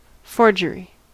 Ääntäminen
Synonyymit counterfeit fake copy Ääntäminen US RP : IPA : /ˈfɔː.dʒər.ɪ/ US : IPA : /ˈfɔːr.dʒər.ɪ/ Haettu sana löytyi näillä lähdekielillä: englanti Määritelmät Substantiivit The act of forging metal into shape .